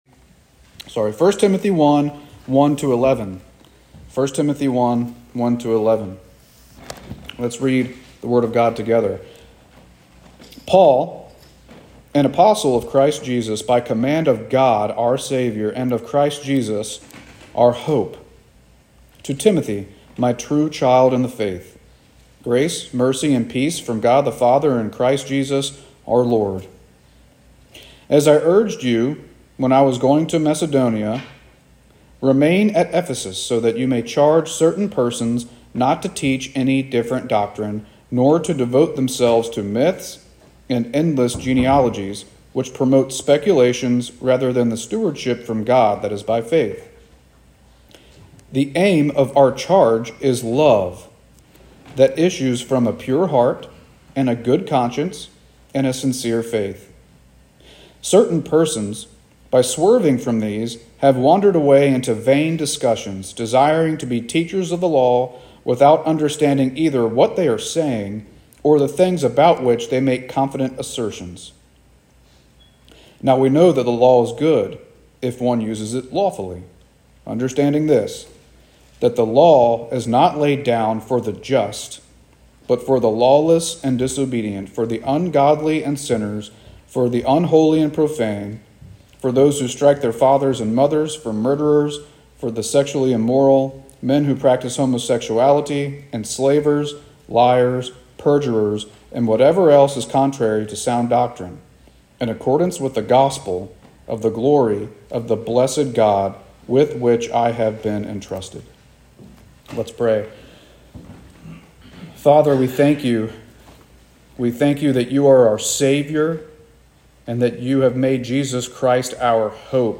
Sermons | Monterey Baptist Church